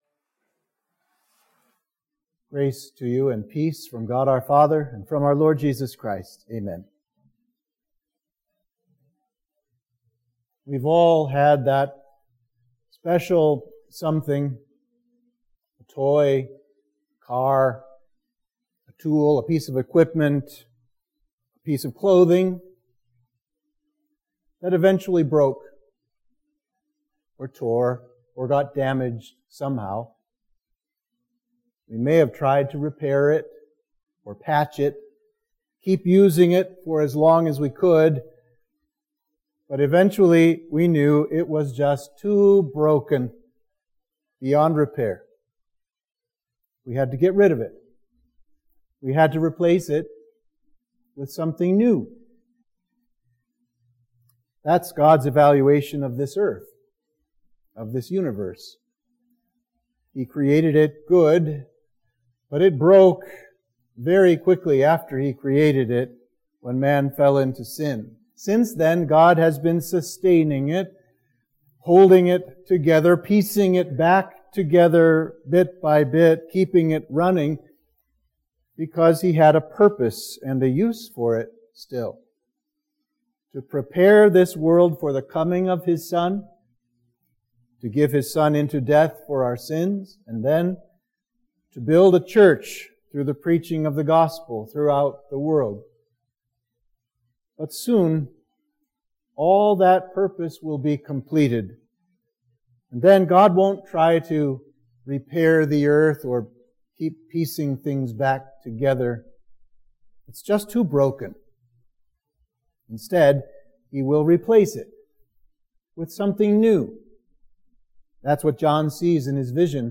Sermon for Midweek of Trinity 22